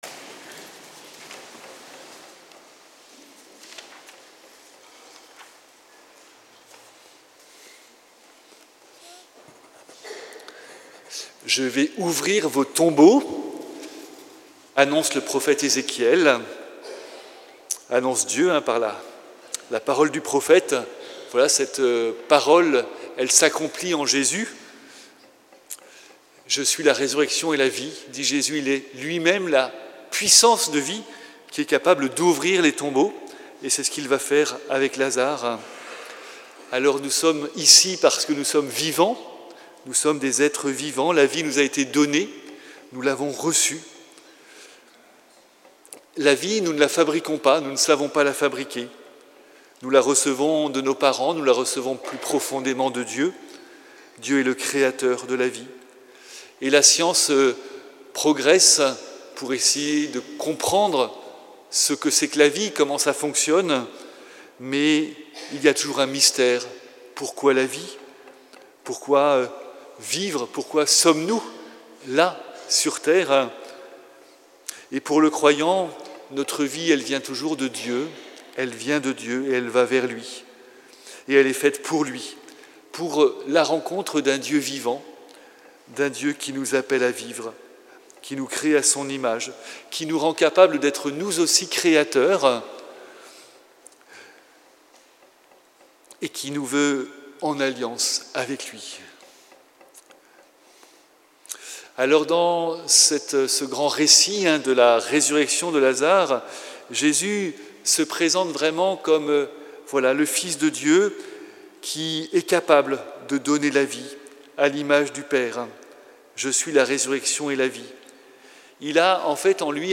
Homélie du 5è dimanche de Carême à Plaisir